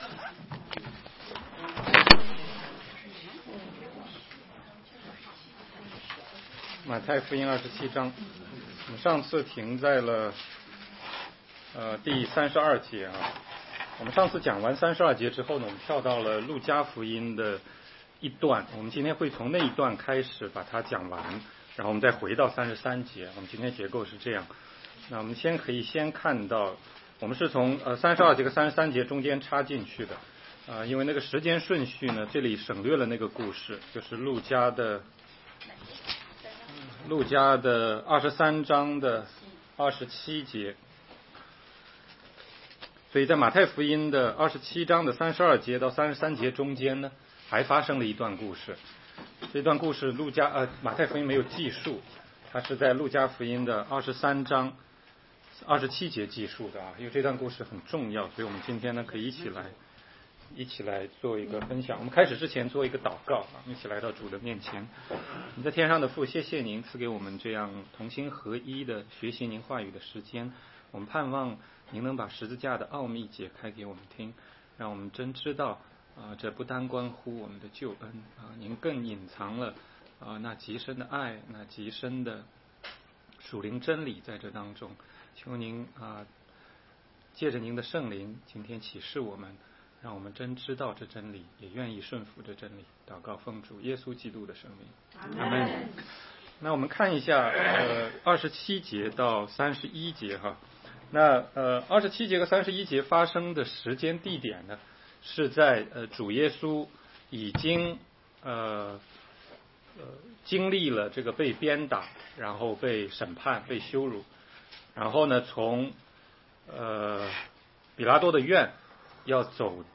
16街讲道录音 - 马太福音27章33-34节：主为什么不喝苦胆调和的酒